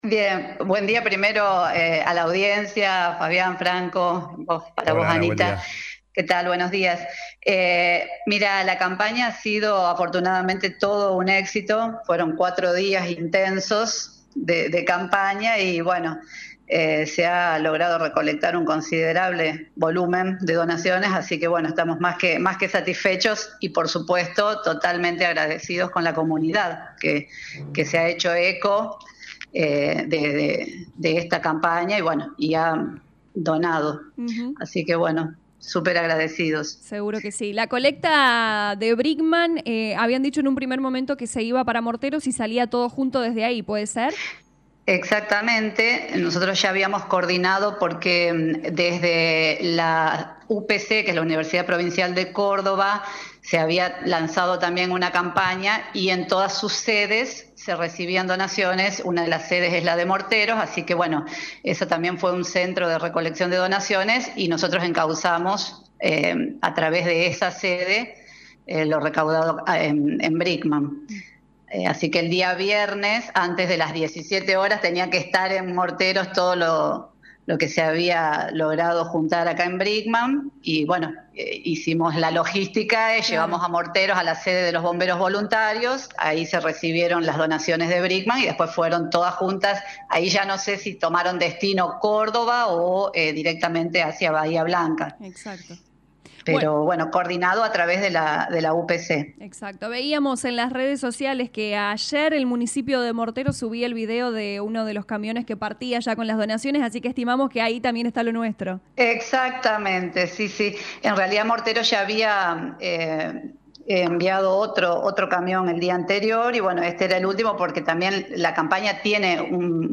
Lo confirmó a LA RADIO 102.9 FM la secretaria de Desarrollo Humano y Políticas Sociales Lic. Analía Frola.